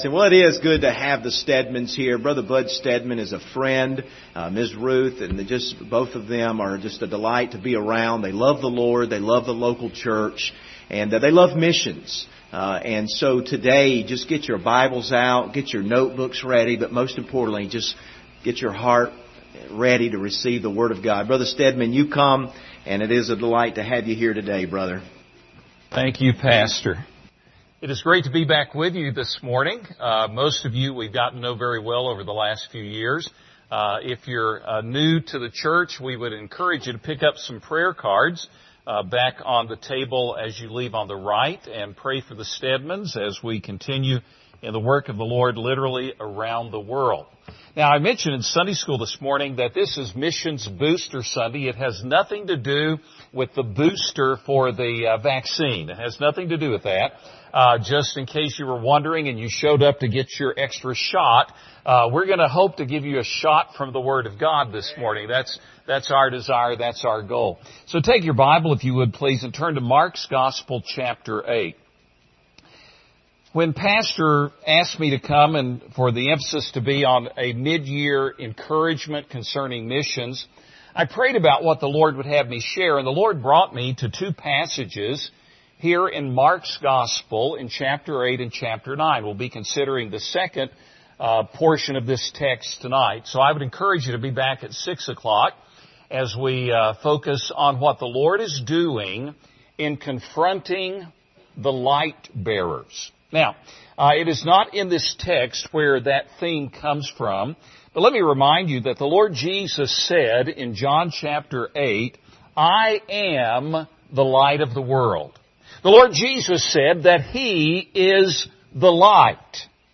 Passage: Mark 8:27-38 Service Type: Sunday Morning Download Files Bulletin Topics